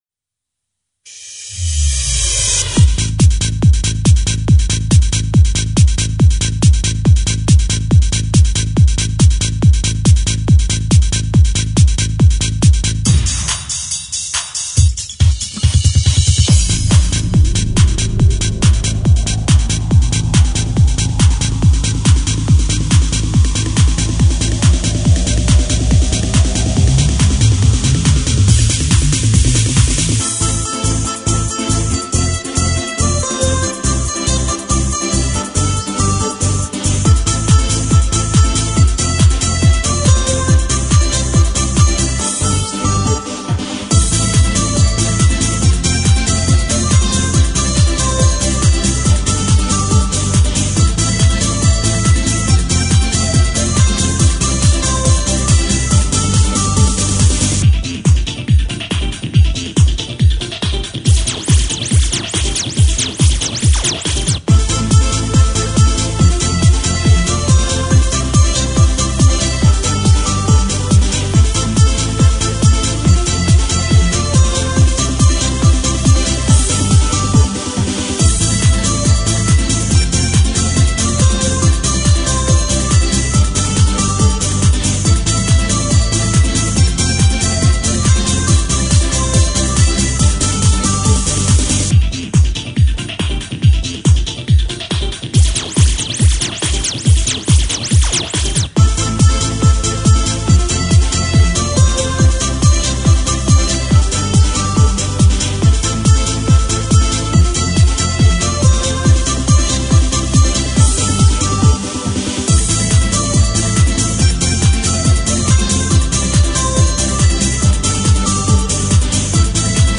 Минусовки